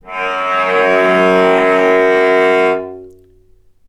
healing-soundscapes/Sound Banks/HSS_OP_Pack/Strings/cello/sul-ponticello/vc_sp-F#2-ff.AIF at 2ed05ee04e9b657e142e89e46e1b34c4bb45e5a5
vc_sp-F#2-ff.AIF